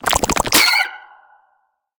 Sfx_creature_penguin_skweak_03.ogg